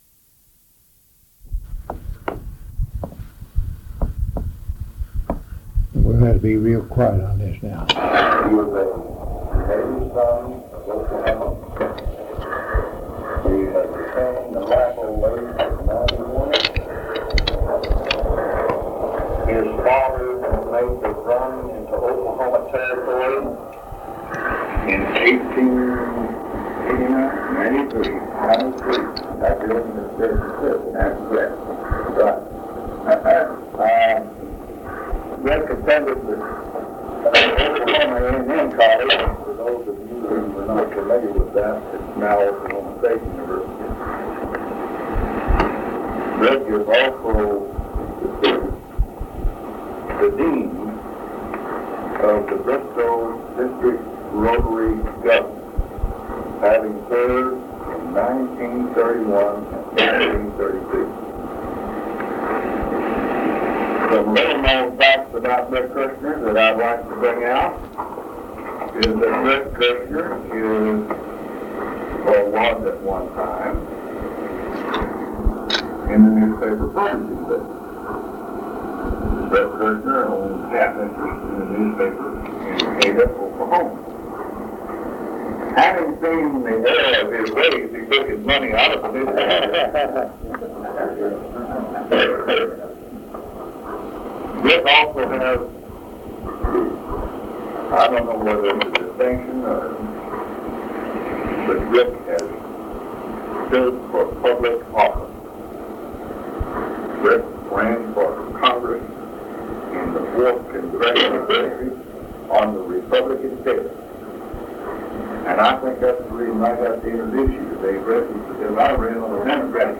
Bristow Historical Society - Oral History Archive | Oil Drilling - The Early Years